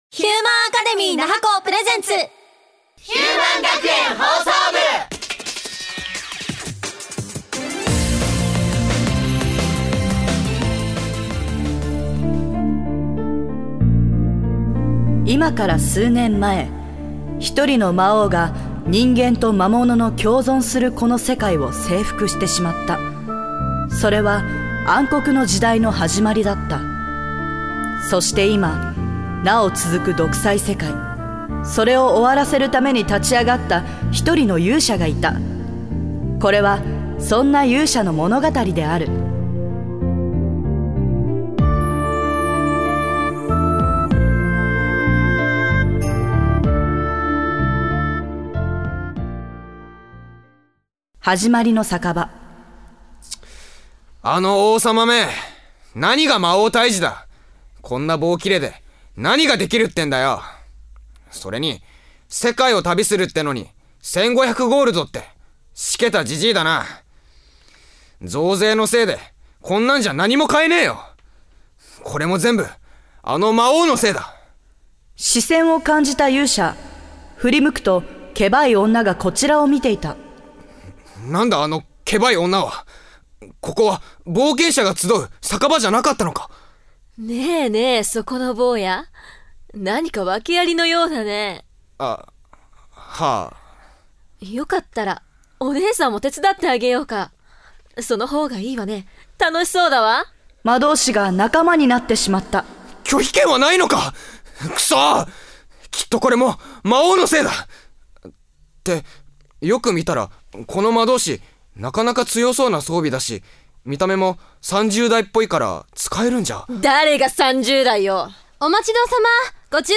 150119ヒューマン学園ラジオドラマ「勇者の冒険」/ヒューマンアカデミー那覇校声優専攻7期